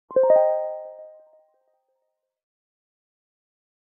snd_respawn.ogg